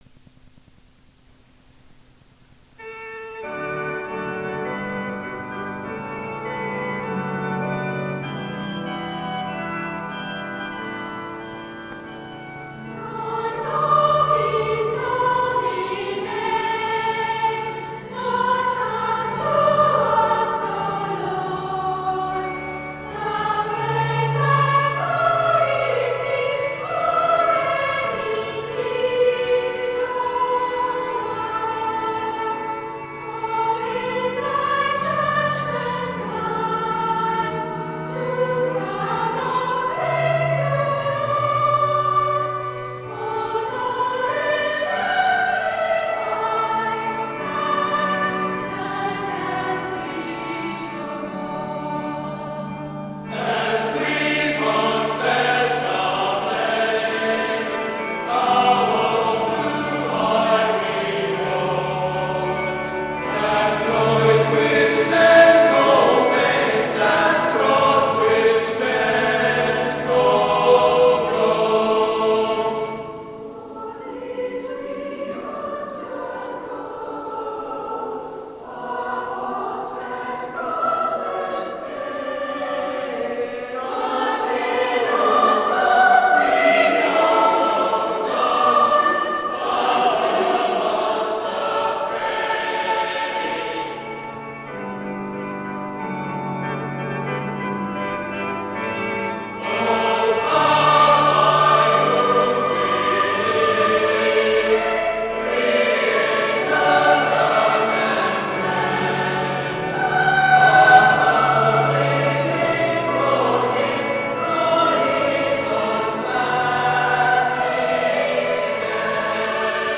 Side A, digitized from cassette tape:
Non Nobis Domine, by R. Kipling (words) and Roger Quilter, composed for the pageant of Parliament in 1936 (senior mixed choir).